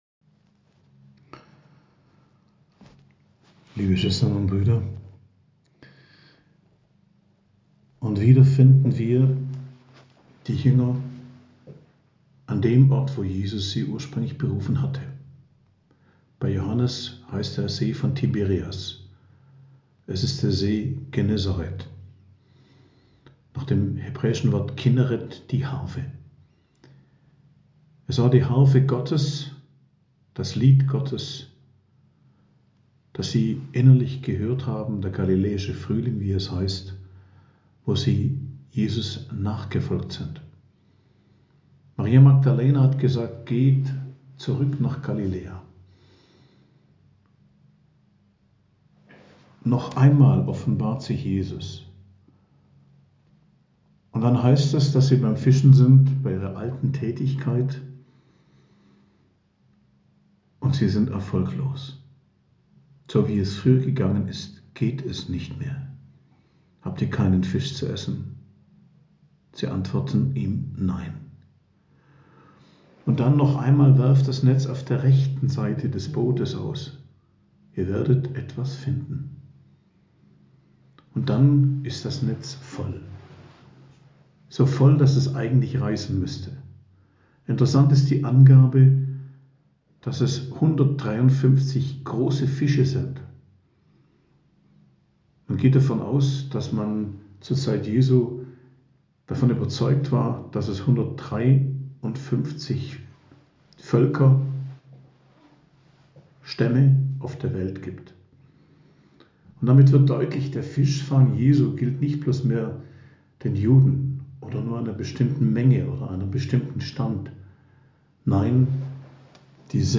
Predigt am Freitag der Osteroktav, 14.04.2023